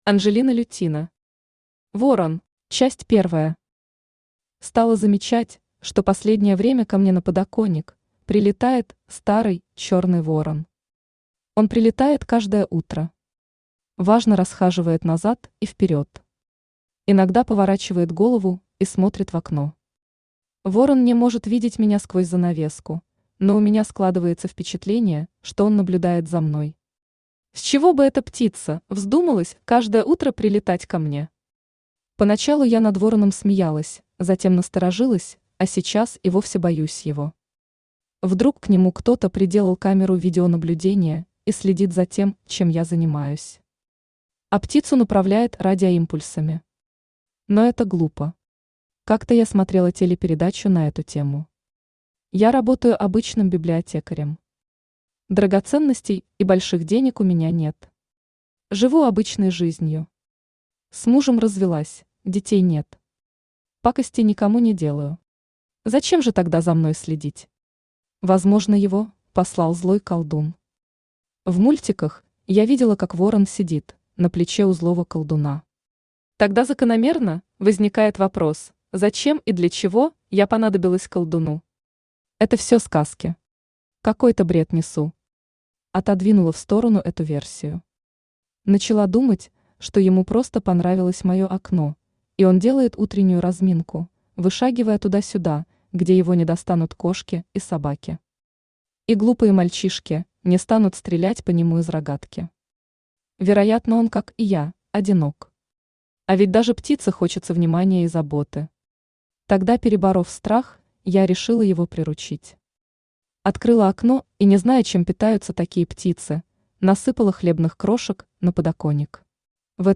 Аудиокнига Ворон | Библиотека аудиокниг
Aудиокнига Ворон Автор Анжелина Ивановна Лютина Читает аудиокнигу Авточтец ЛитРес.